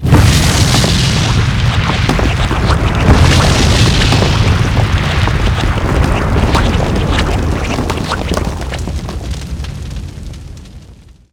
volcano.ogg